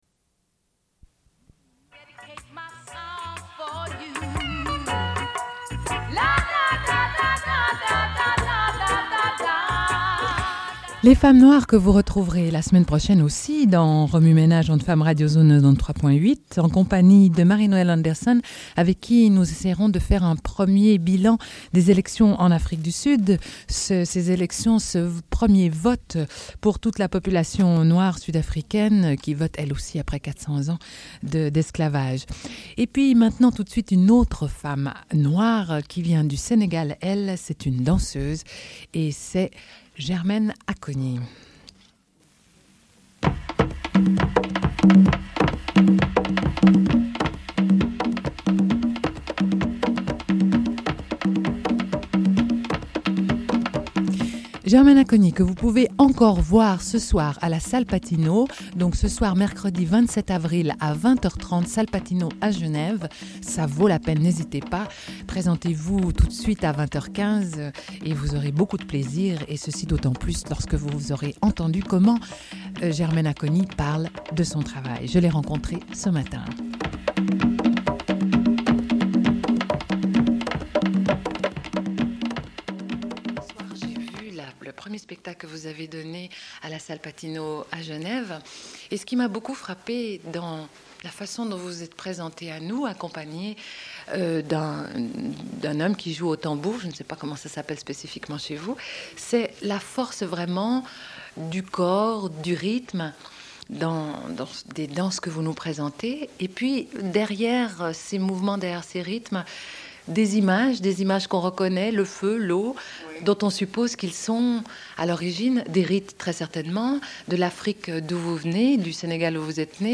Une cassette audio, face A31:20
Sommaire de l'émission : Interview de Germaine Acogny, danseuse sénégalaise.